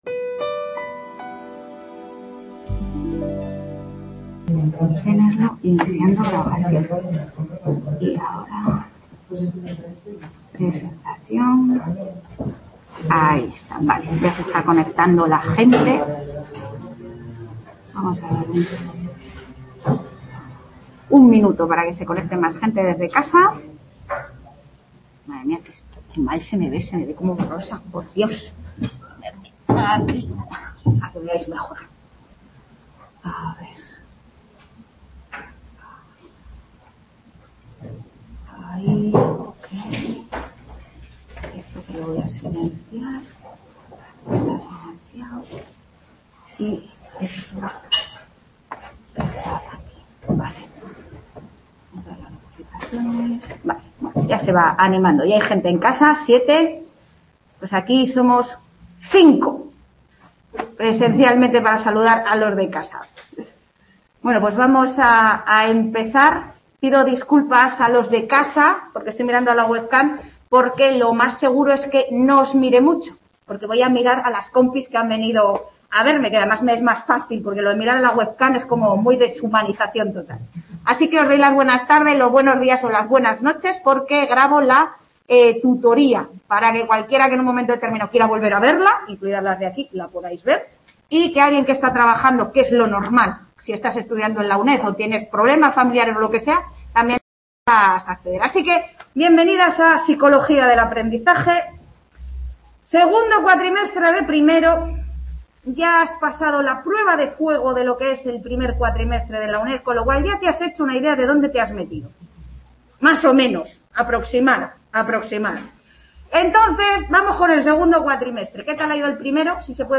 Tutoría de presentación